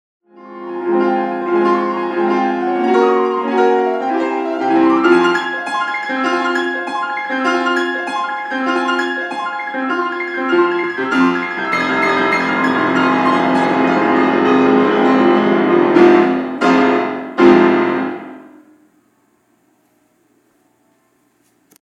The way he ends it is abrupt, and with a clear movement towards the darker colors of the piano. Again, it’s almost violent.